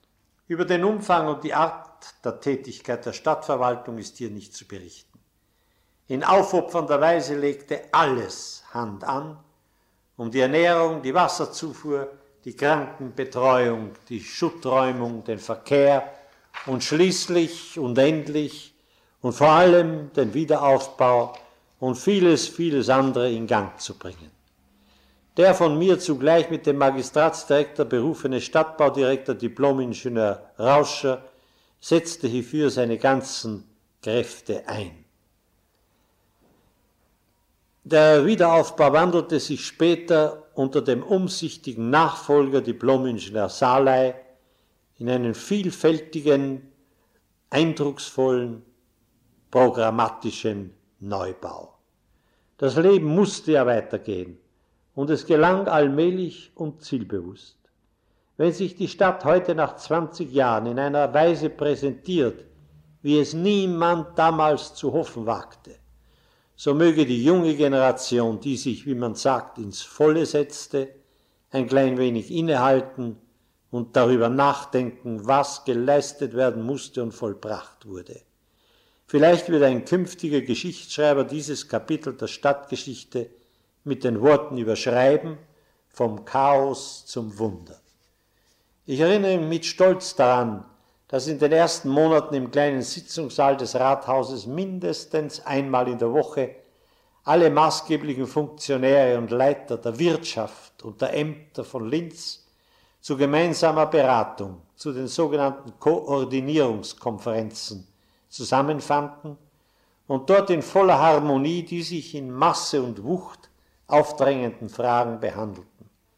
Interview mit Ernst Koref zum Wiederaufbau in Linz